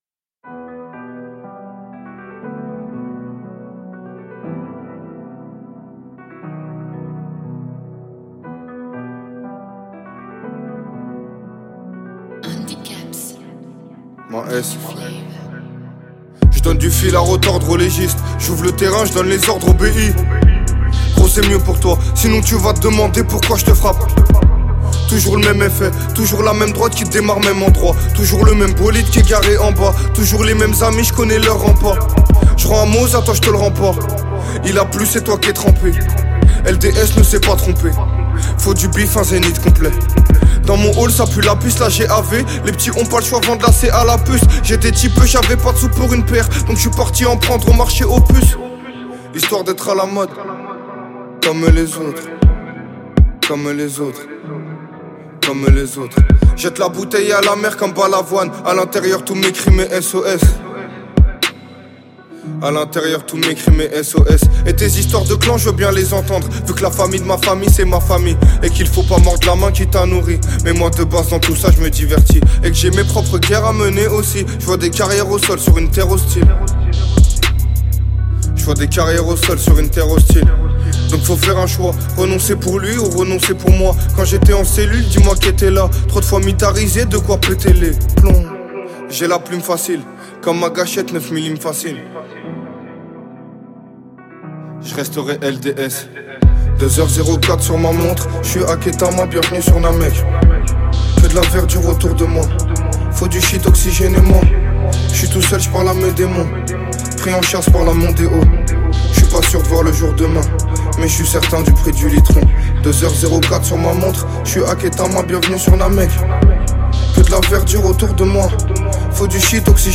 45/100 Genres : french rap, pop urbaine Télécharger